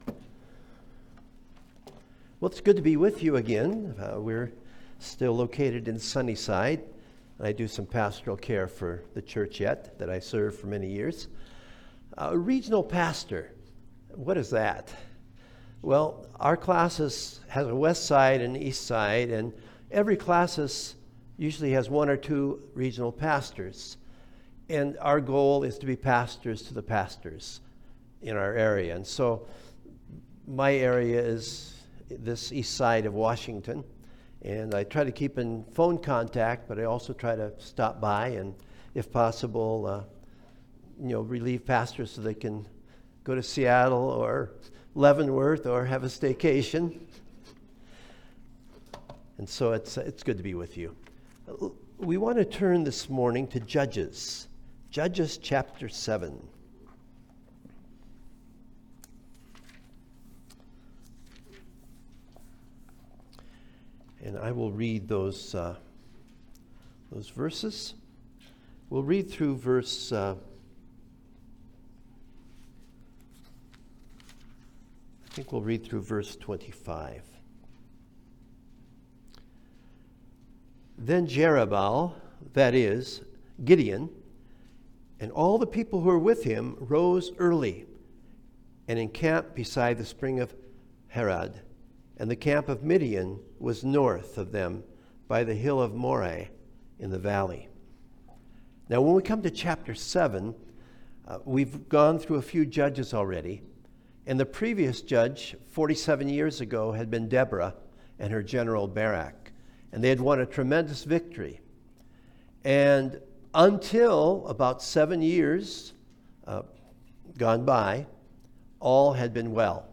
Passage: Judges 7 Service Type: Sunday Service